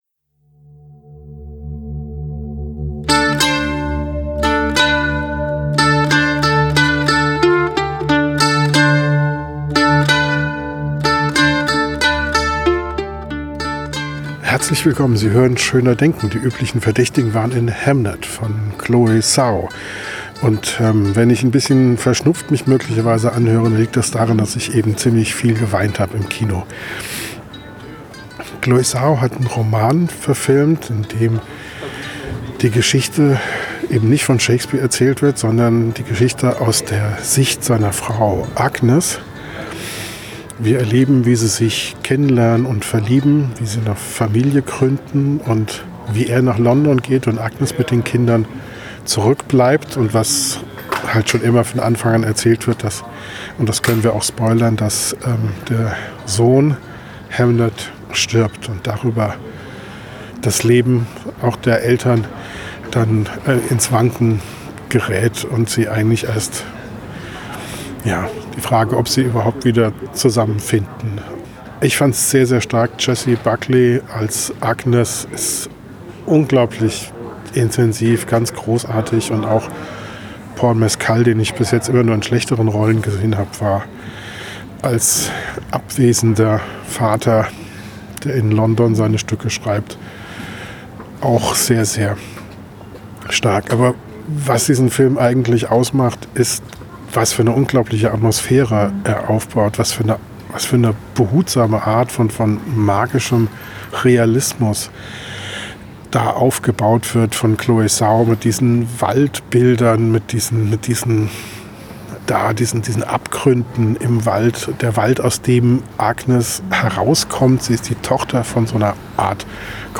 Der erste Eindruck direkt nach dem Kino